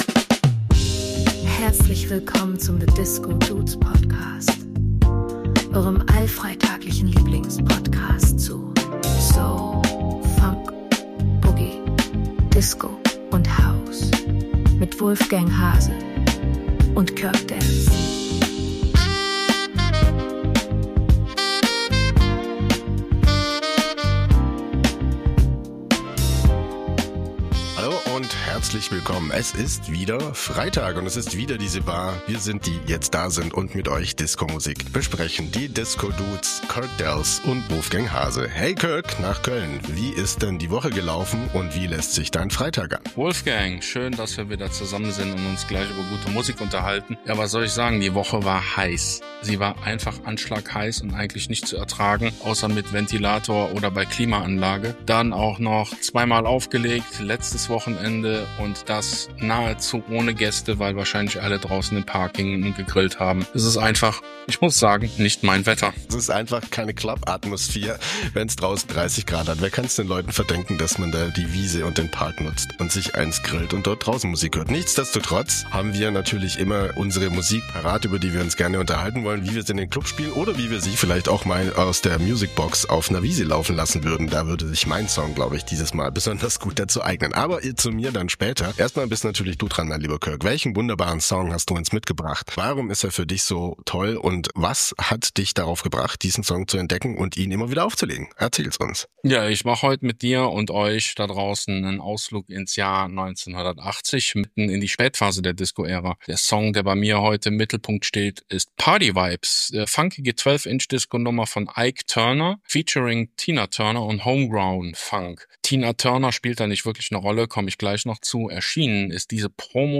🪩 P-Funk-Power trifft Jazz-Soul-Eleganz
Post-Disco-Experimente und entspannte Park-Chill&Party-Vibes